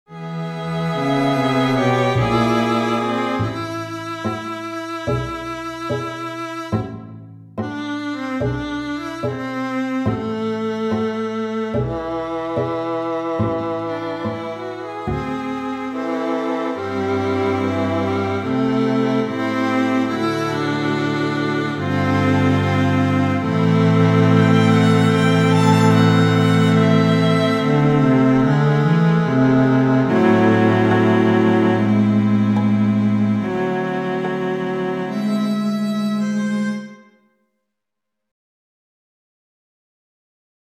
for Solo Viola & String Quintet